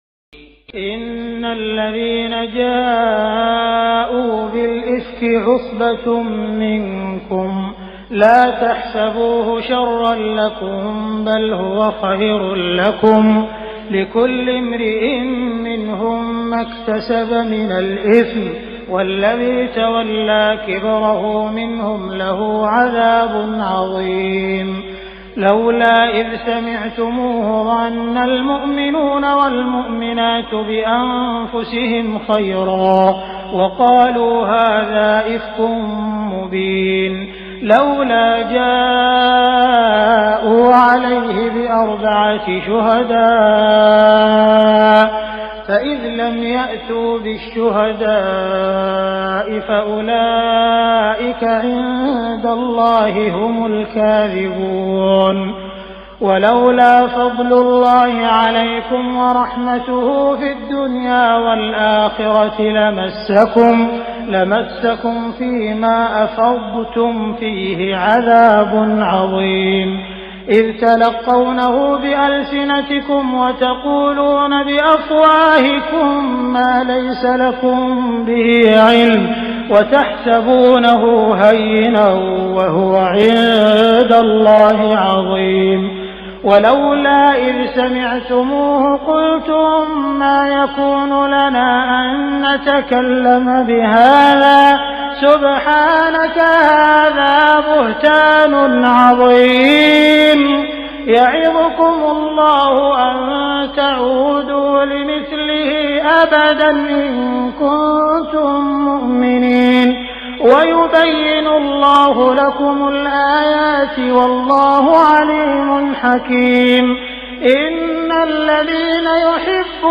تراويح الليلة السابعة عشر رمضان 1418هـ من سورتي النور (11-64) و الفرقان (1-20) Taraweeh 17 st night Ramadan 1418H from Surah An-Noor and Al-Furqaan > تراويح الحرم المكي عام 1418 🕋 > التراويح - تلاوات الحرمين